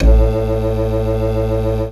54_19_organ-A.wav